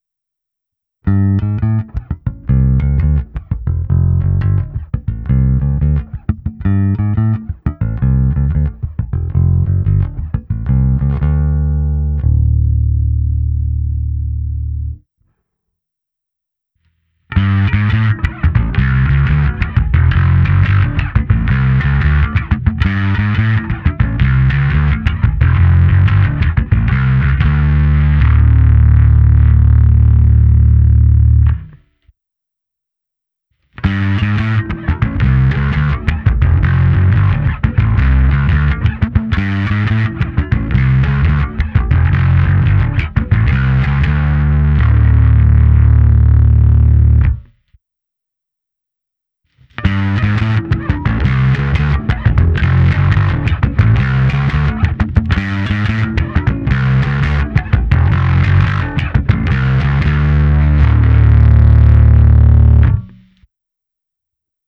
Alpha je jemnější zkreslení, Omega naopak drsné.
Je to hned první zkreslený zvuk v ukázce, která začíná čistým zvukem baskytary s kompresorem, základní ekvalizací a také přidanou simulací aparátu, pak právě "moje" popsané zkreslení, další zkreslení je se stejným nastavením, ale MOD byl na půlce mezi α a Ω, a nakonec MOD plně na Ω.